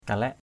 kalaik.mp3